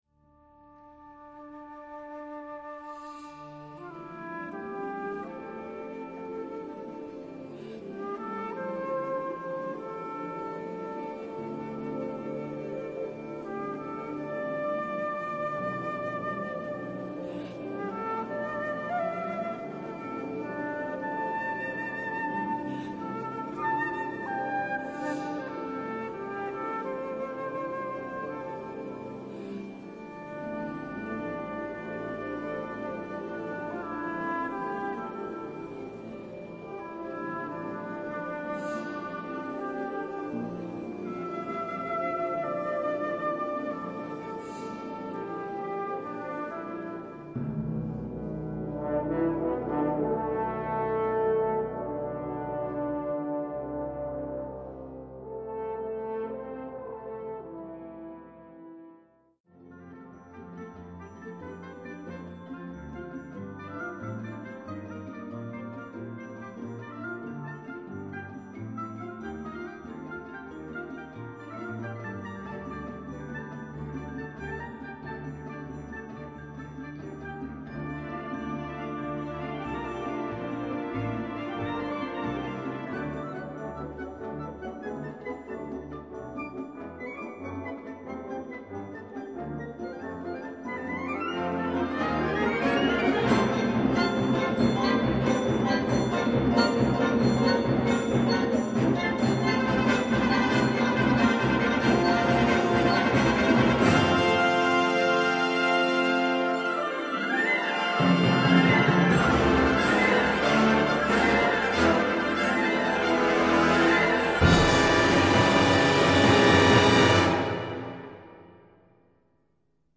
Gattung: Sinfonisches Gedicht
Besetzung: Blasorchester